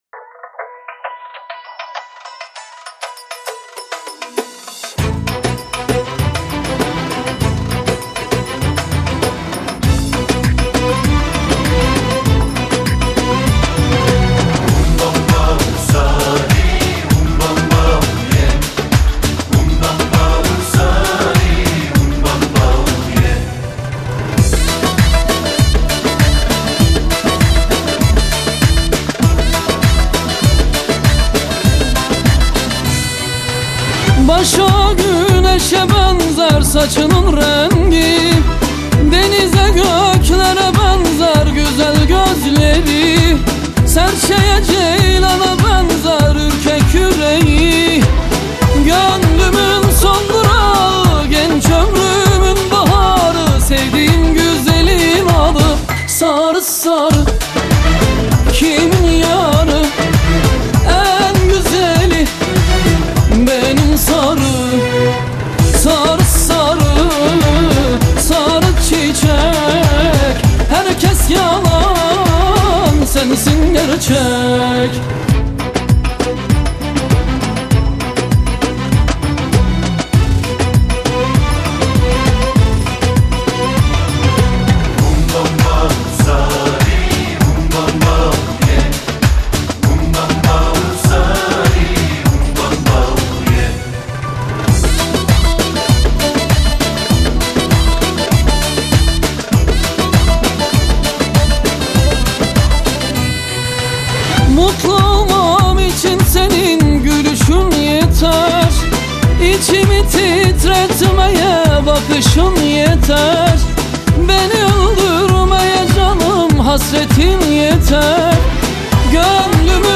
.mp3 اینم شاد